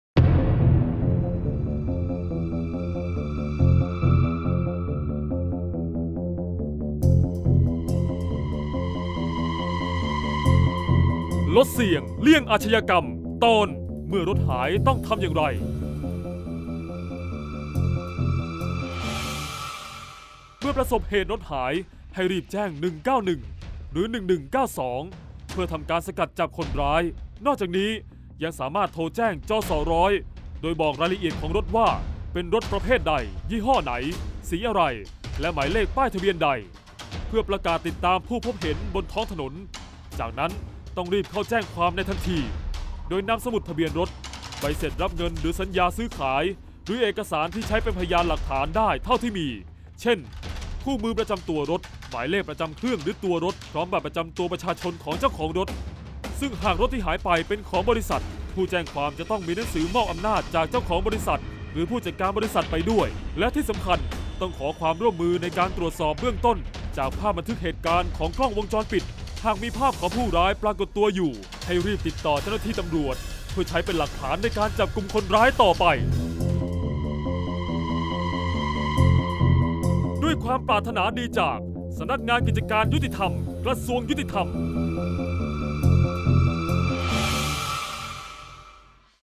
เสียงบรรยาย ลดเสี่ยงเลี่ยงอาชญากรรม 44-รถหายทำอย่างไร